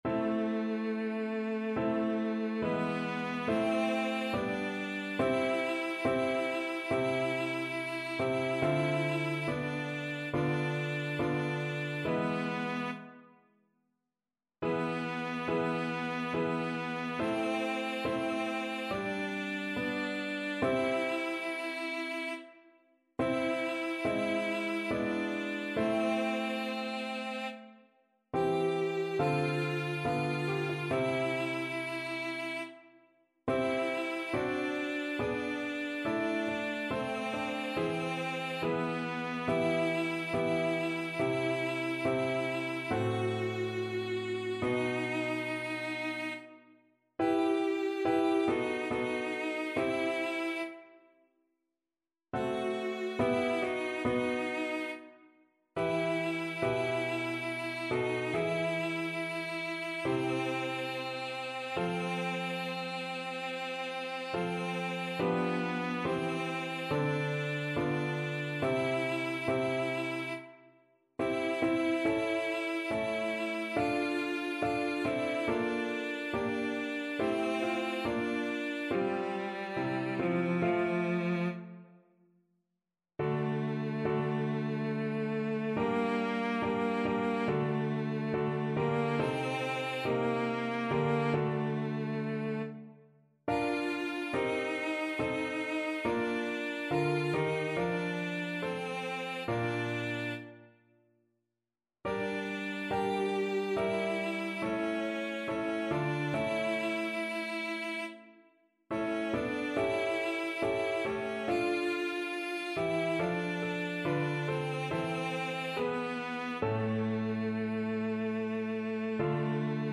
Classical Croft, William Burial Sentences (as used in the funeral of Queen Elizabeth II) Cello version
Cello
A minor (Sounding Pitch) (View more A minor Music for Cello )
2/4 (View more 2/4 Music)
Classical (View more Classical Cello Music)